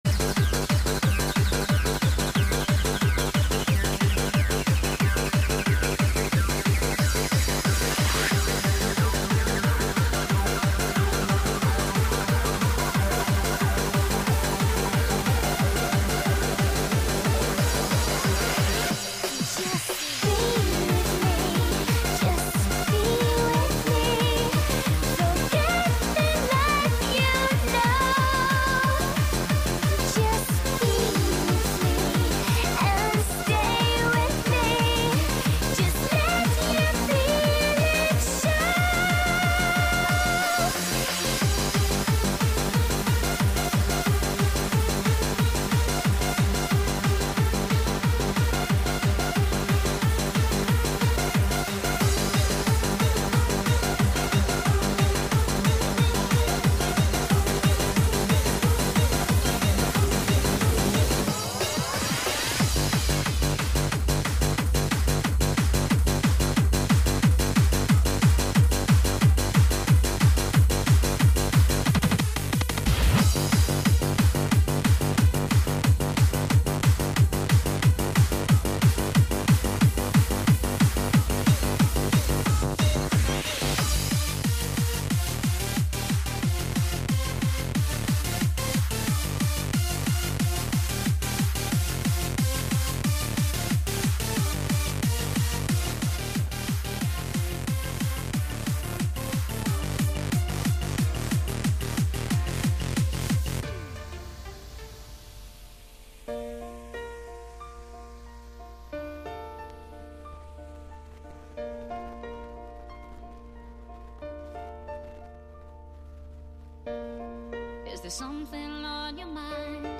Happy Hardcore Vocals Rave